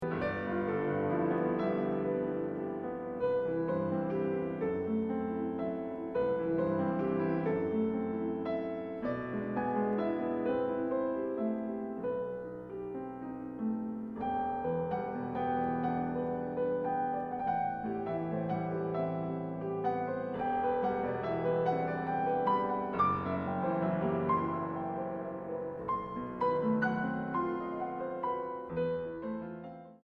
Andantino Tranquilo 2:20